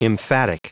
Prononciation du mot emphatic en anglais (fichier audio)
Prononciation du mot : emphatic